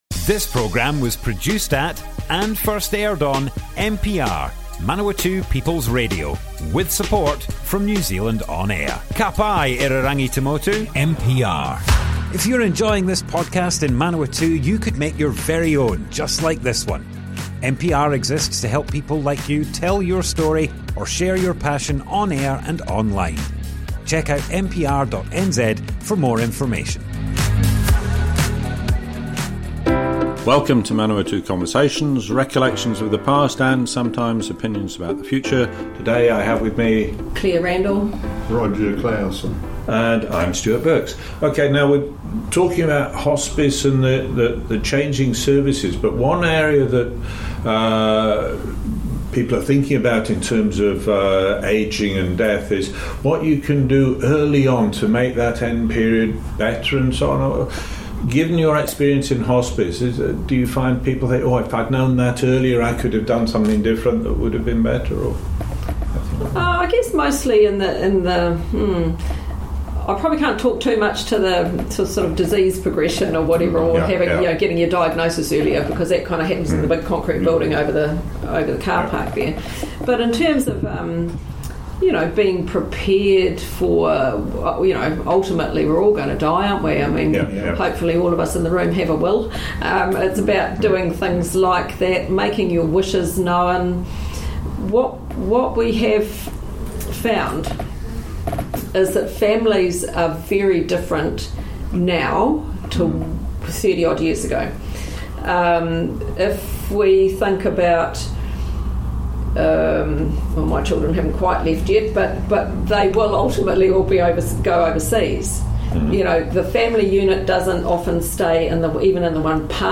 00:00 of 00:00 Add to a set Other Sets Description Comments Arohanui Hospice Part 2 - Manawatu Conversations More Info → Description Broadcast on Manawatu People's Radio, 21st May 2024.
oral history